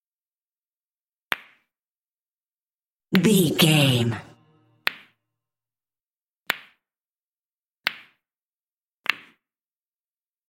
Billards balls hit
Billards balls hit 250
Sound Effects